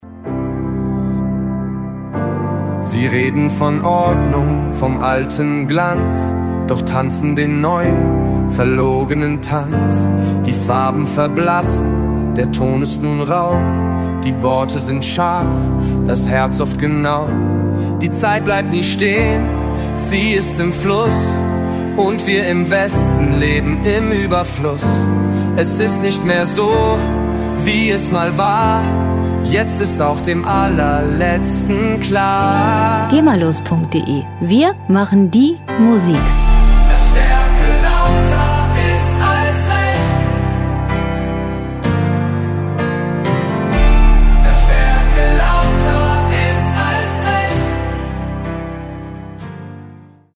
Pop Musik aus der Rubrik
Musikstil: Pop-Hymne
Tempo: 127 bpm
Tonart: D-Dur
Charakter: hoffnungsvoll, politisch